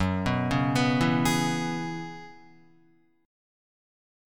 F#7sus2sus4 chord